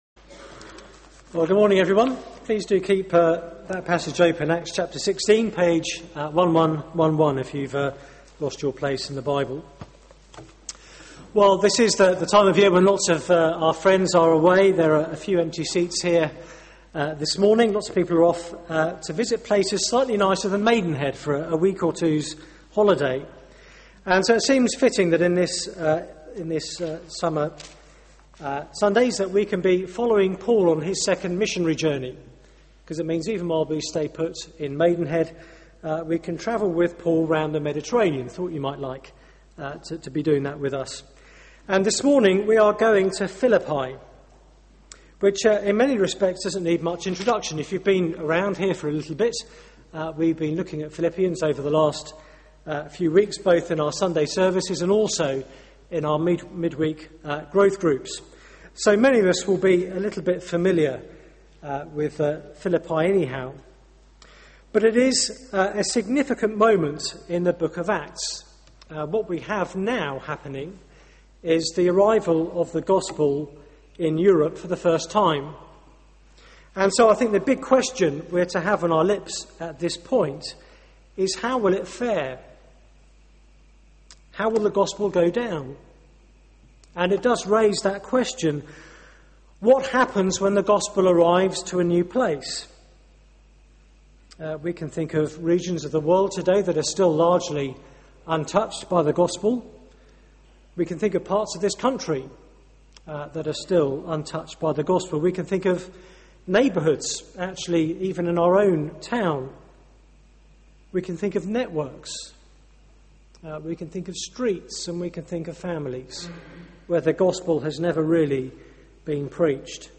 Media for 9:15am Service on Sun 31st Jul 2011 09:15 Speaker
Sermon